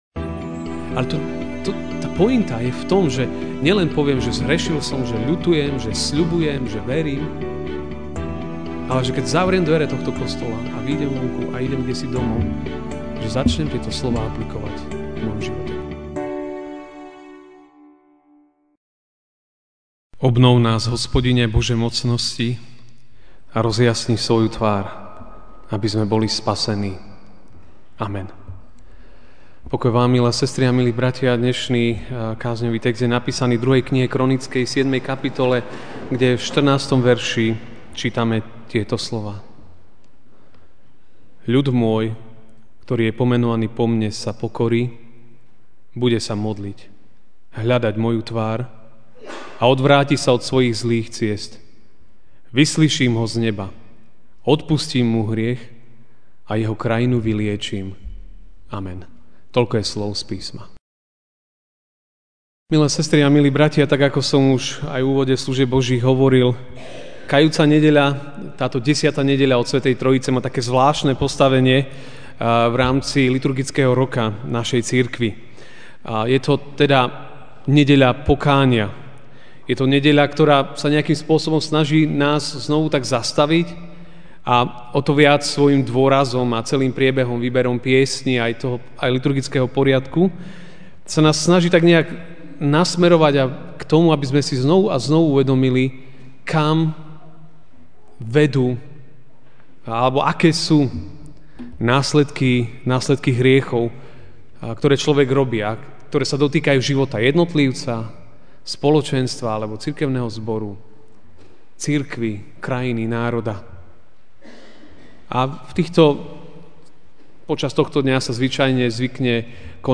Ranná kázeň: Božia cesta k odpusteniu a uzdraveniu (2. Kron. 7, 14) ... ľud môj, ktorý je pomenovaný po mne, sa pokorí, bude sa modliť, hľadať moju tvár a odvráti sa od svojich zlých ciest: vyslyším ho z neba, odpustím mu hriech a jeho krajinu vyliečim.